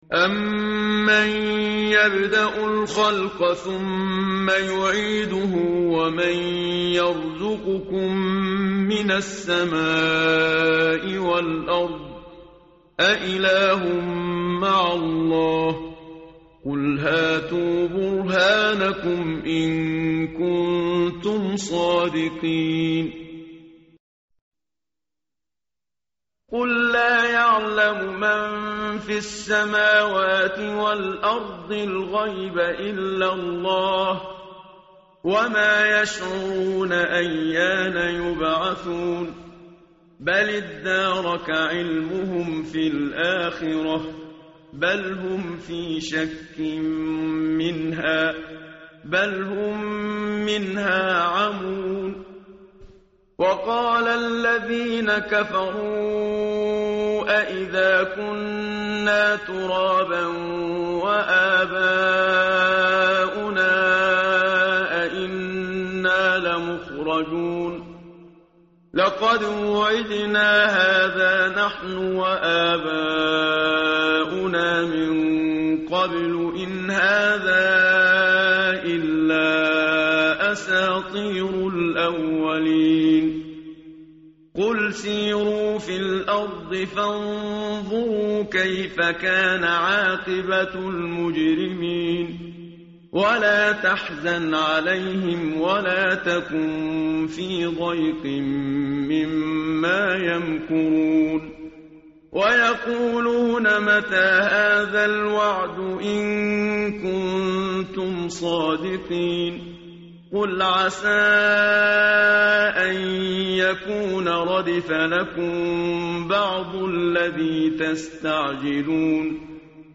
متن قرآن همراه باتلاوت قرآن و ترجمه
tartil_menshavi_page_383.mp3